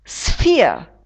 sphere [sfiə]